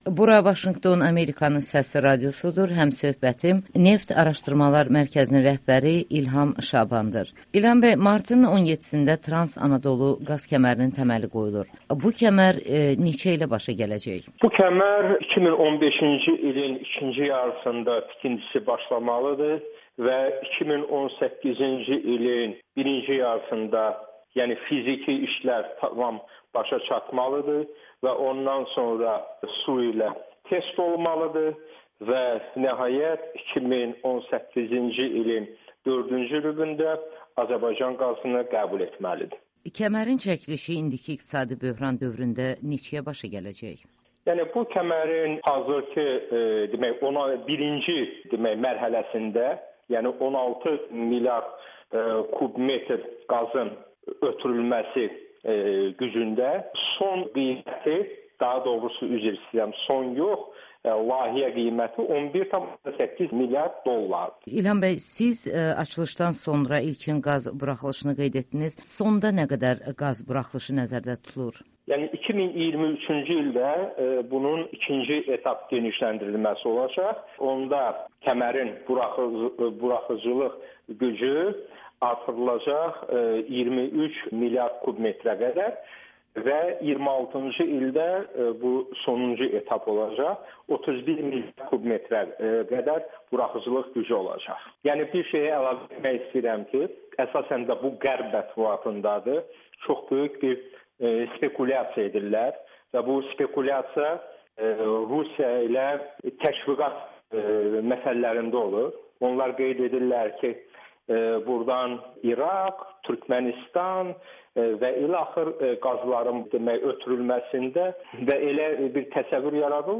Trans Anadolu 2018-ci ildə Azərbaycan qazını qəbul edəcək [Audio-Müsahibə]